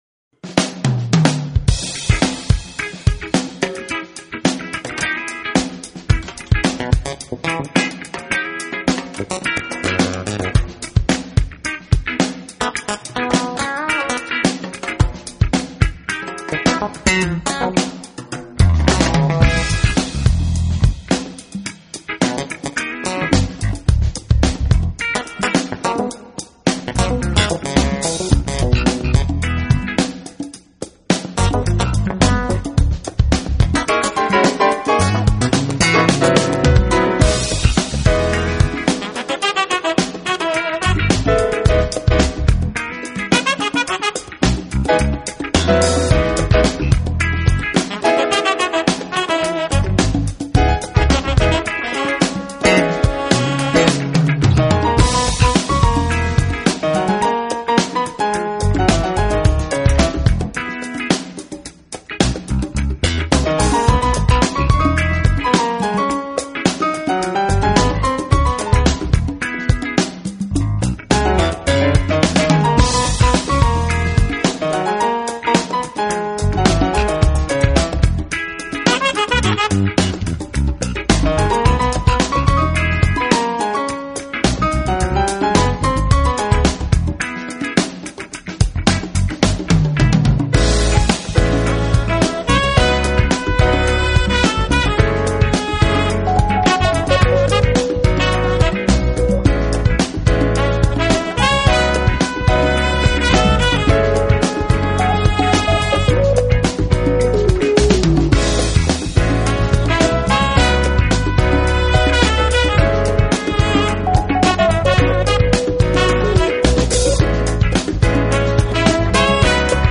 Sax leads over some dreamy